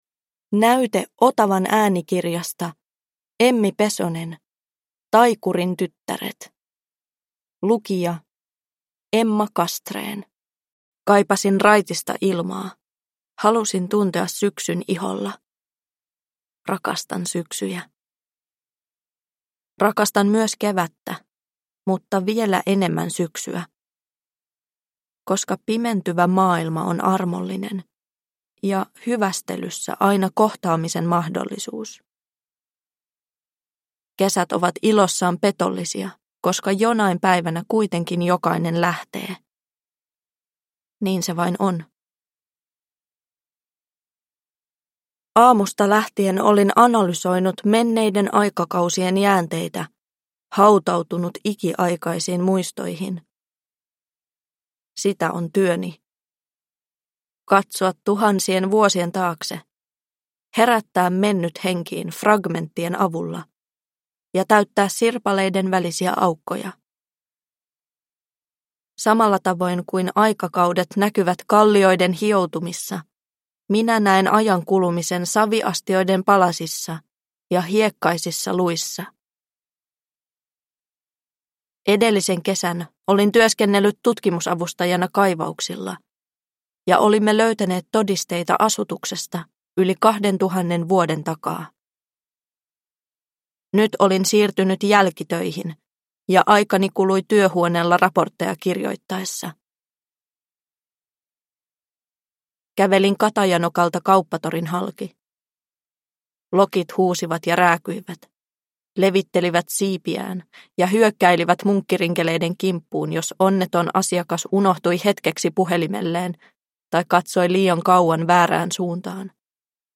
Taikurin tyttäret – Ljudbok – Laddas ner